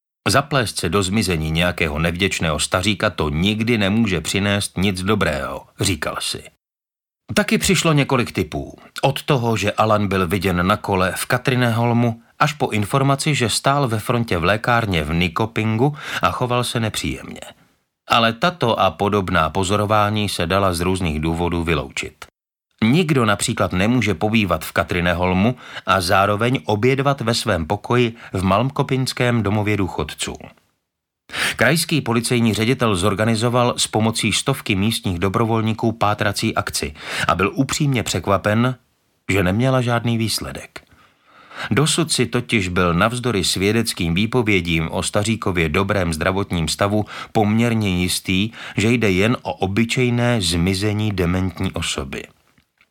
Stoletý stařík, který vylezl z okna a zmizel audiokniha
Ukázka z knihy
stolety-starik-ktery-vylezl-z-okna-a-zmizel-audiokniha